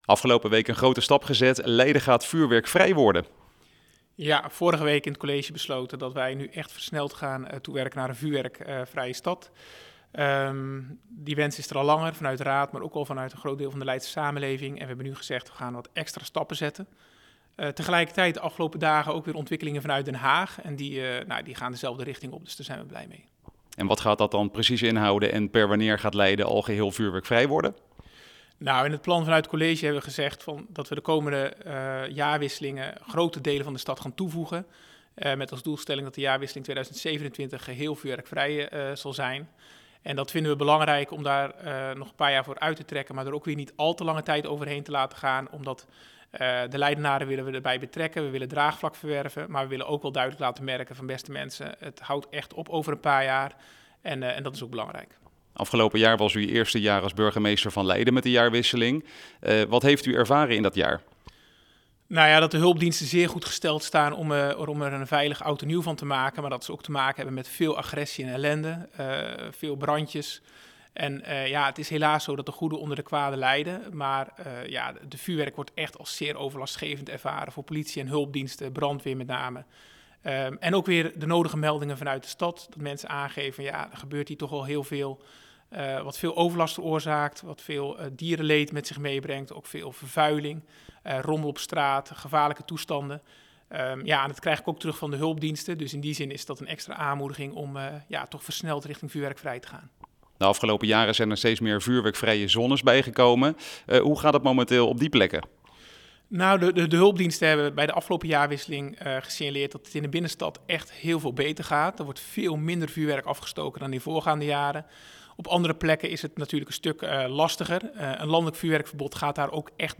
in gesprek met burgemeester Peter Heijkoop over het vuurwerkverbod in Leiden.